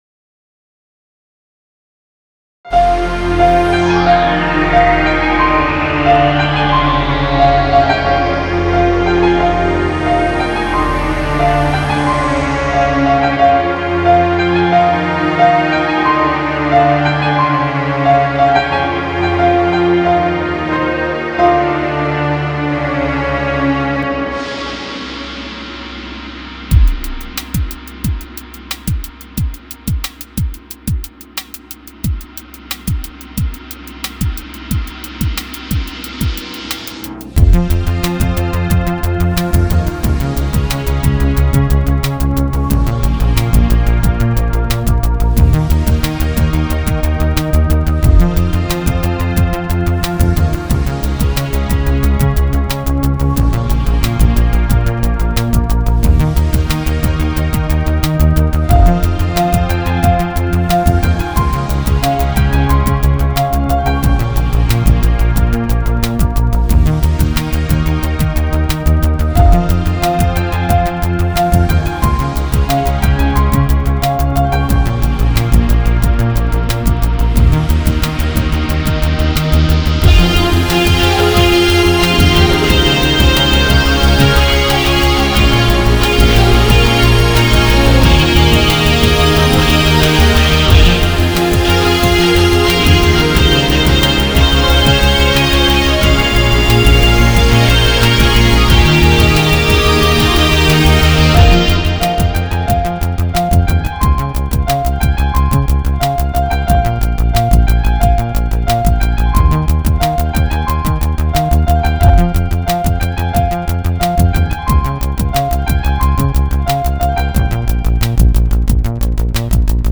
Original Works Serotonin Waveforms This is an adaptation of a Piano solo I wrote for music class. It is a more full out remix.